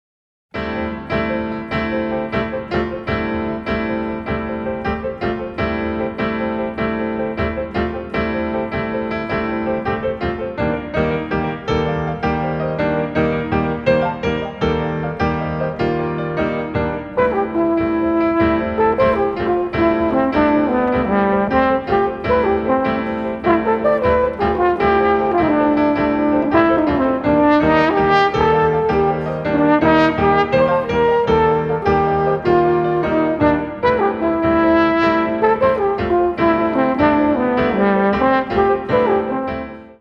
the two outstanding Swiss vocalists